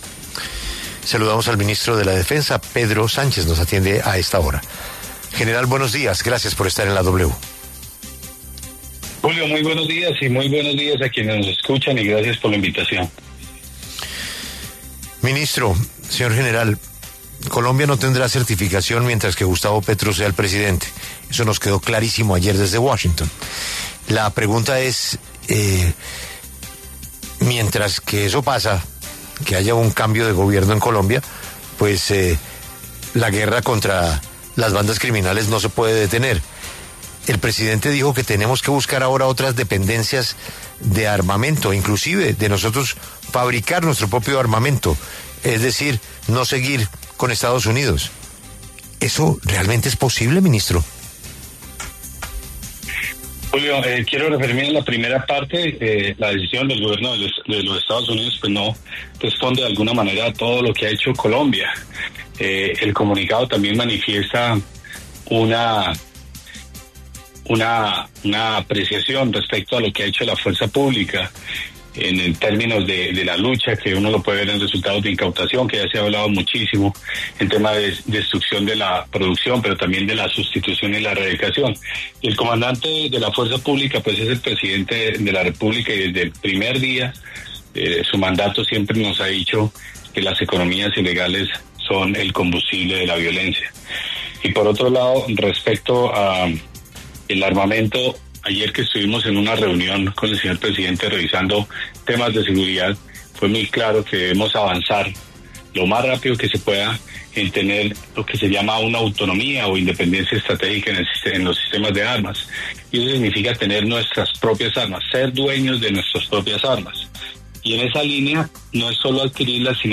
El ministro de Defensa, Pedro Sánchez, conversó con La W sobre la propuesta del presidente Petro de dejar de depender de Estados Unidos para armamento.